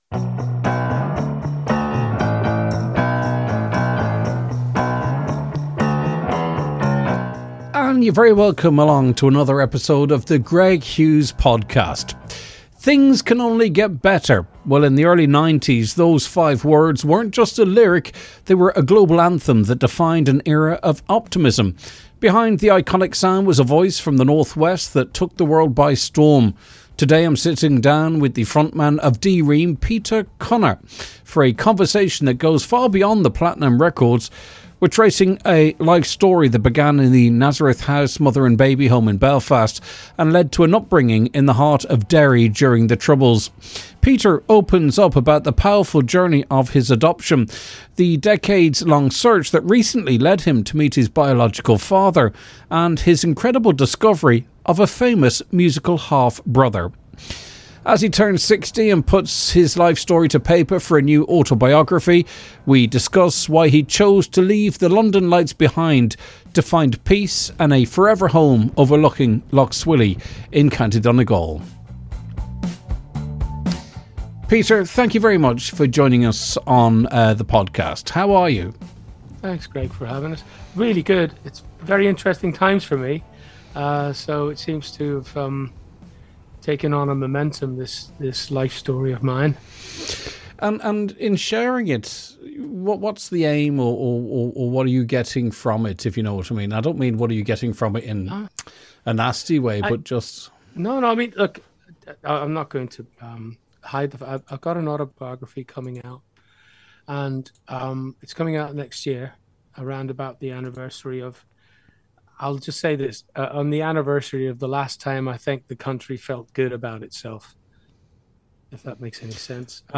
In this candid interview, Peter takes us behind the platinum records and the bright lights of the 90s. We explore a life story that began at the Nazareth House mother and baby home, moved through the streets of Derry, and eventually led to a global stage.